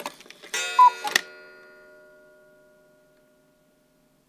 cuckoo_strike1.L.wav